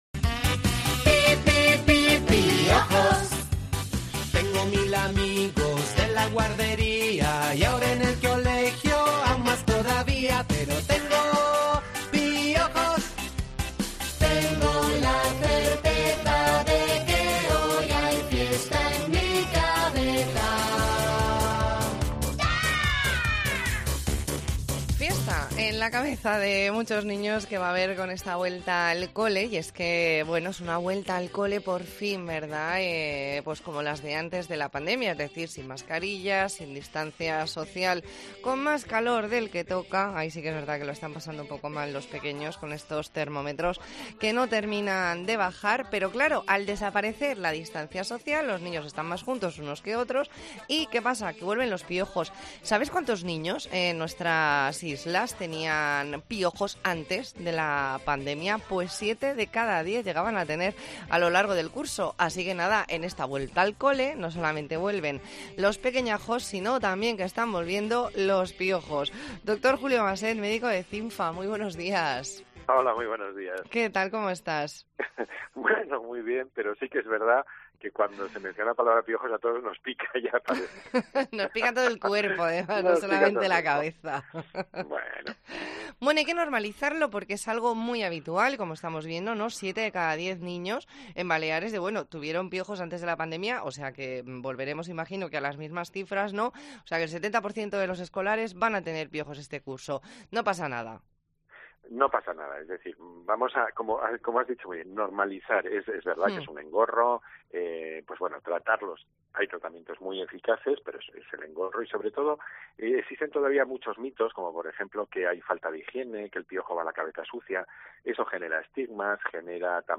ntrevista en La Mañana en COPE Más Mallorca, martes 13 de septiembre de 2022.